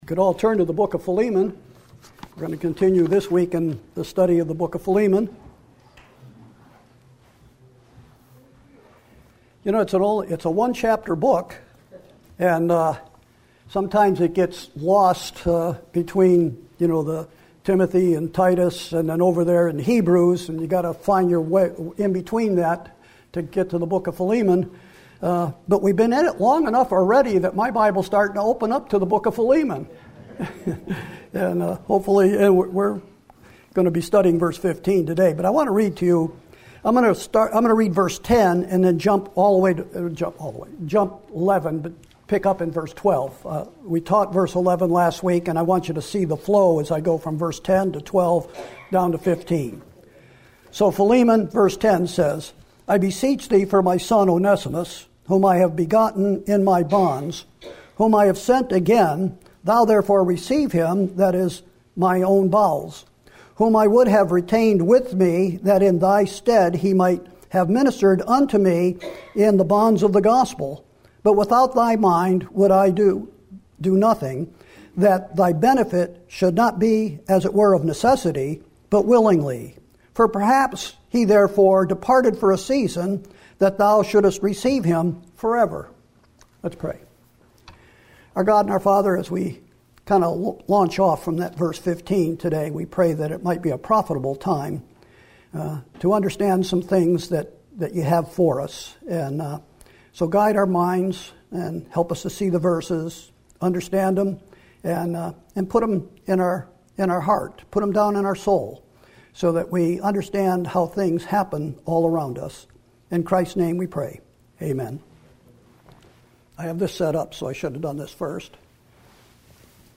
Sermons & Single Studies / Sunday 11am